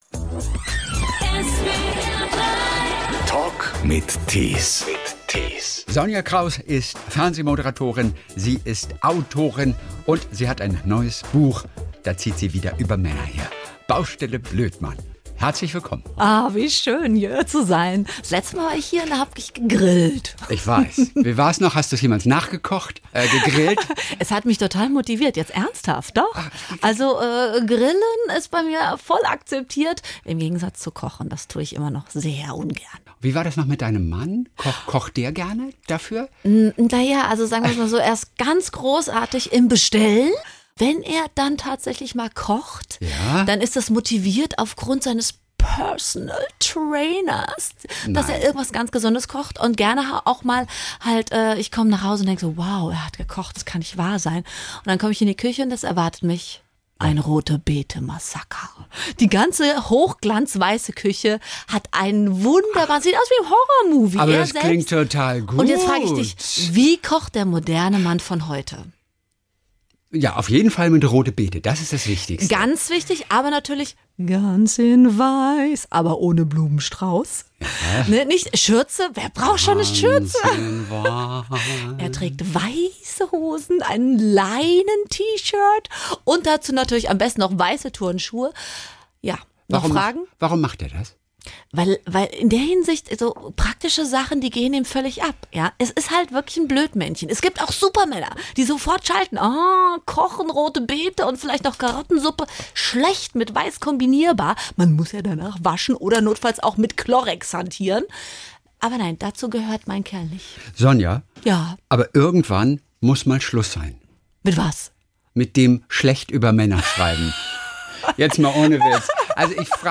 Der Talk mit interessanten Menschen